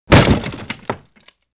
Break Wood Effect.wav